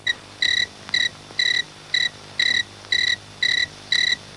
Cricketjam Sound Effect
cricketjam.mp3